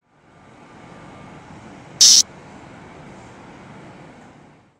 Агрессивный вопль богомола